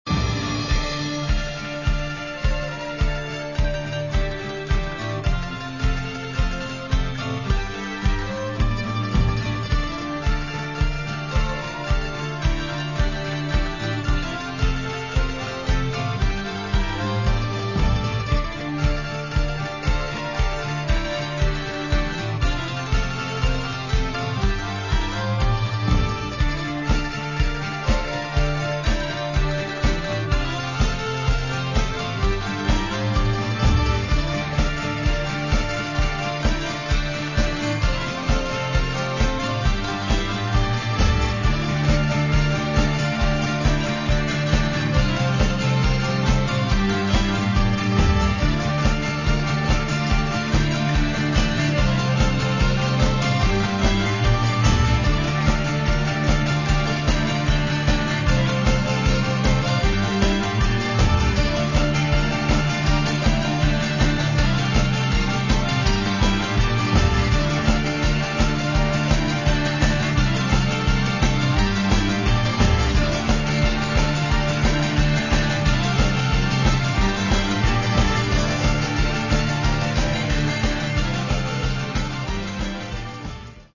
Catalogue -> Rock & Alternative -> Punk